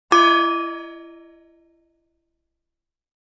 Descarga de Sonidos mp3 Gratis: gong 12.